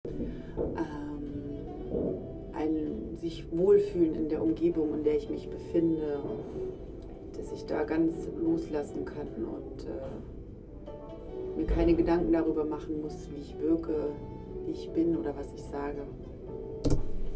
Standort der Erzählbox:
FONA Forum @ Berlin, Futurum